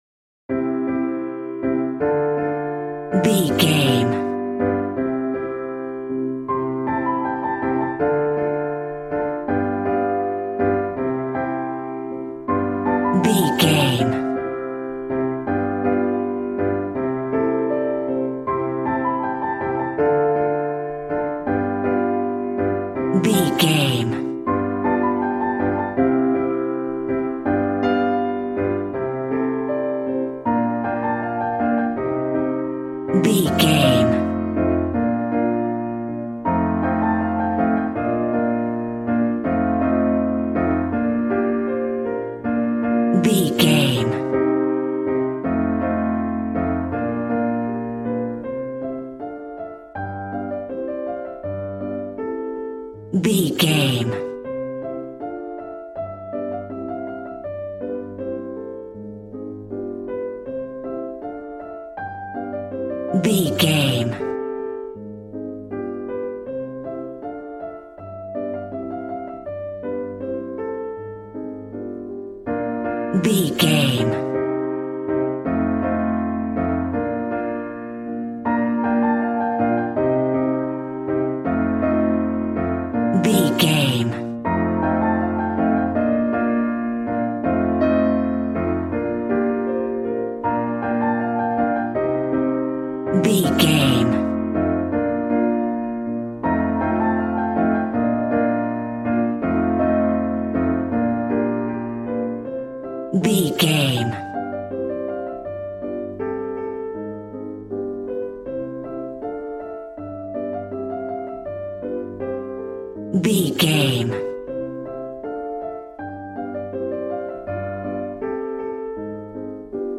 Ionian/Major
passionate
acoustic guitar